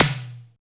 TECHTOM LO.wav